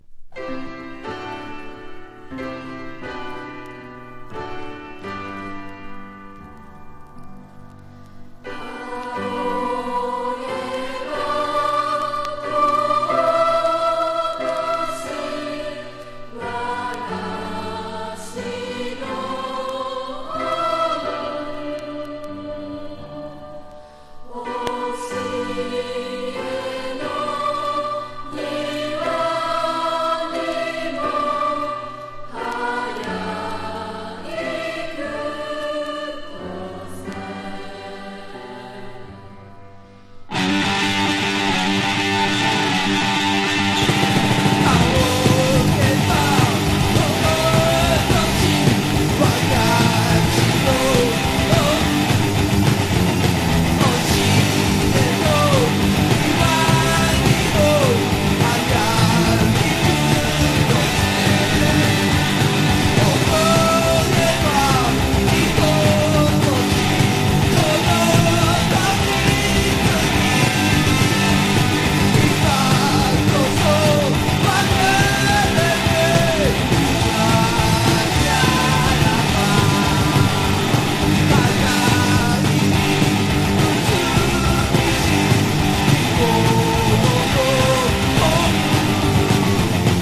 60-80’S ROCK# PUNK / HARDCORE